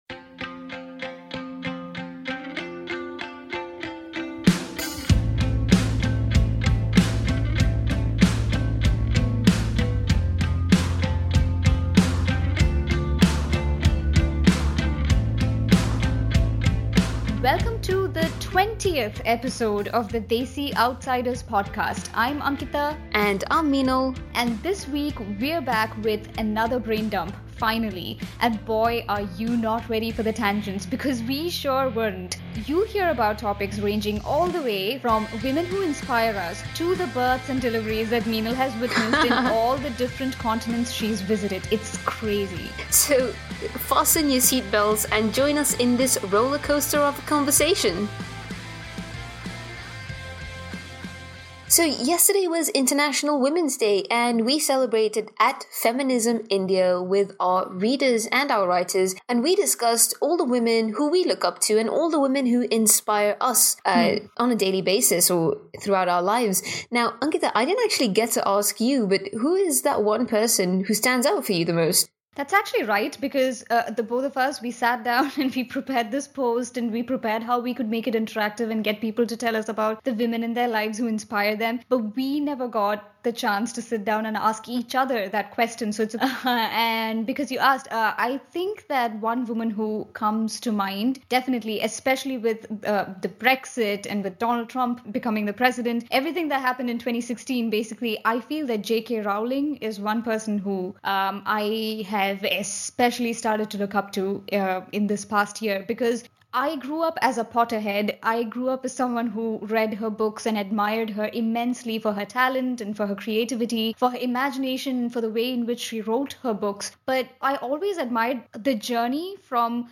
Fasten your seatbelts, this is one rollercoaster of a conversation.